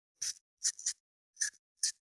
502桂むき,大根の桂むきの音切る,包丁,厨房,台所,野菜切る,咀嚼音,ナイフ,調理音,
効果音厨房/台所/レストラン/kitchen食材